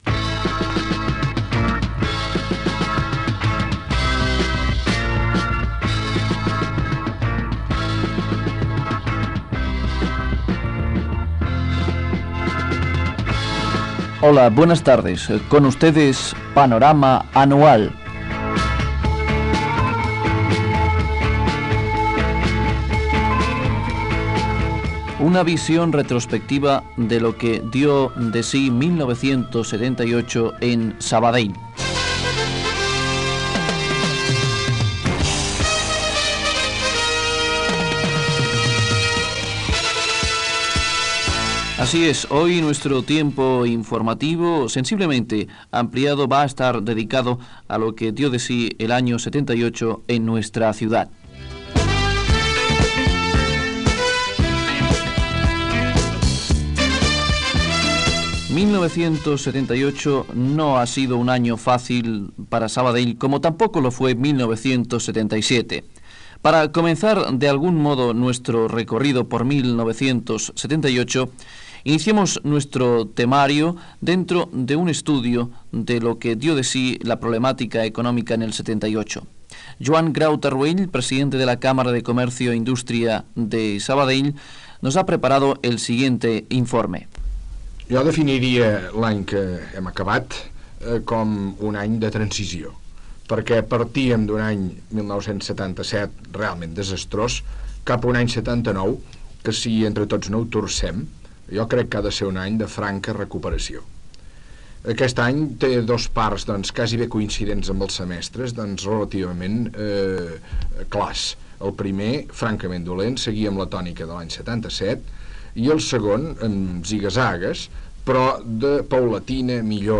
863413e8c4afcce20c8a61821a5164e19f52e619.mp3 Títol Ràdio Sabadell EAJ-20 Emissora Ràdio Sabadell EAJ-20 Titularitat Privada local Nom programa Panorama anual 1978 Descripció Sintonia, presentació, resum informatiu de l'any 1978 a Sabadell. Amb valoracions dels representants locals dels partits polítics i dels sindicats Comissions Obreres (CCOO), Unió General de Treballadors (UGT) i Unió Sindical Obrera (USO) Gènere radiofònic Informatiu